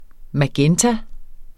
Udtale [ maˈgεnta ]